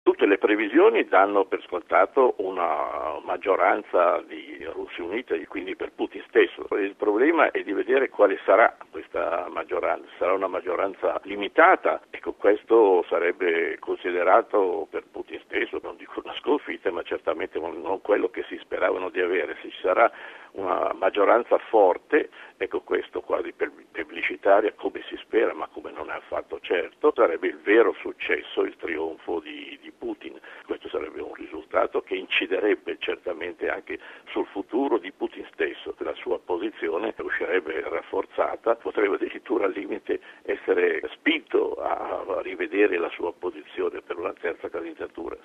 esperto di storia e politica russa